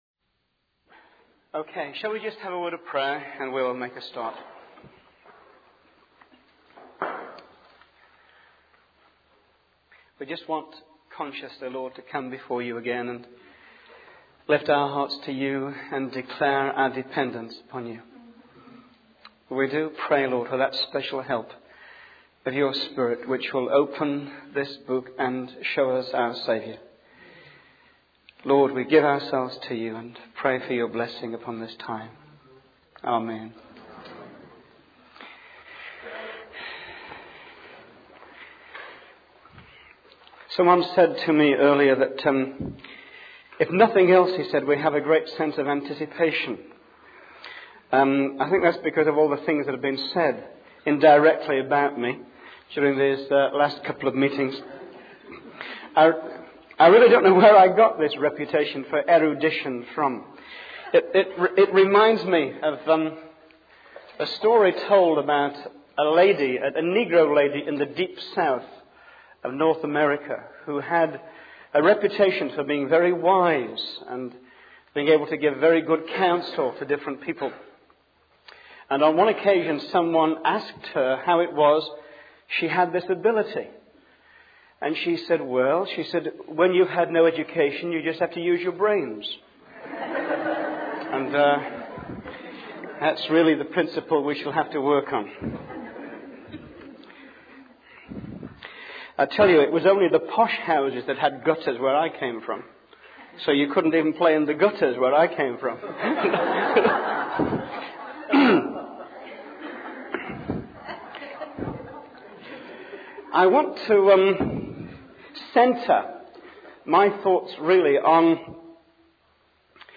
In this sermon, the speaker emphasizes that God does not shy away from hopeless cases, despite our limited resources and narrow hearts. He highlights how God speaks to nations and individuals, making great promises even when he knows they may not come to fruition.